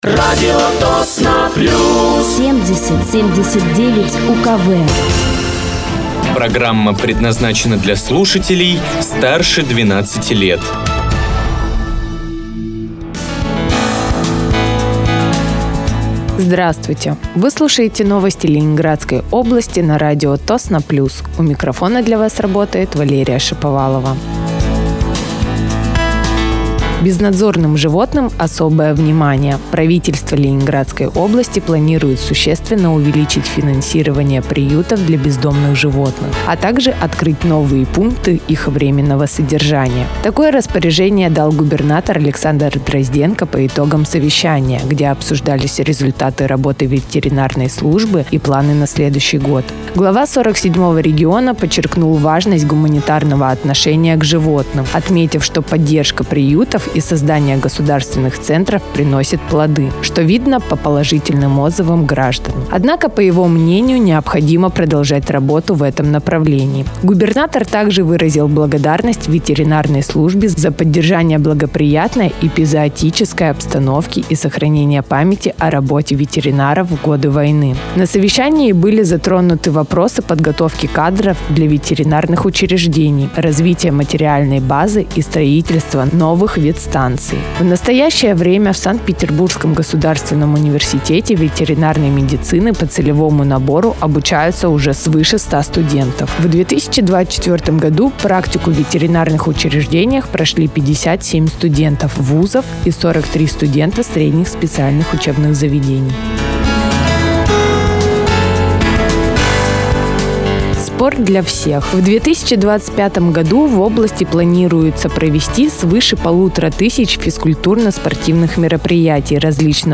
Вы слушаете новости Ленинградской области на радиоканале «Радио Тосно плюс».